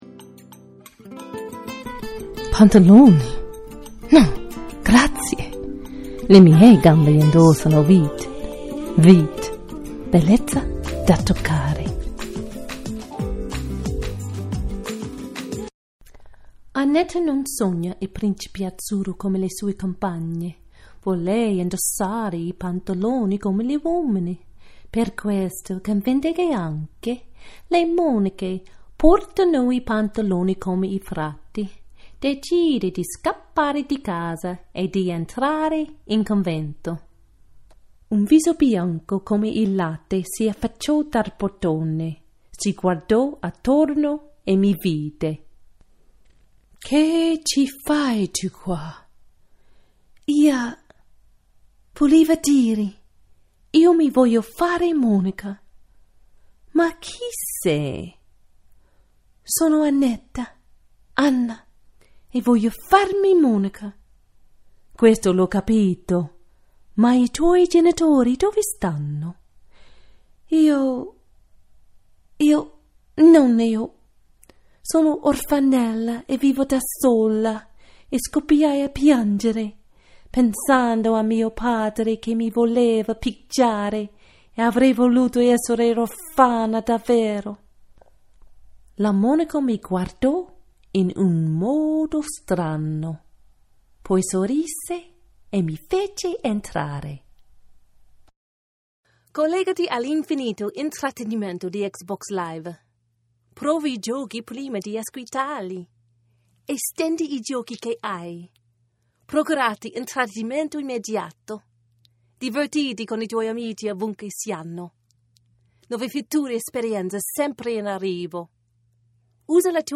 An American English speaker with a confident, clear, soothing, positive and sometimes sassy voice.
Sprechprobe: Werbung (Muttersprache):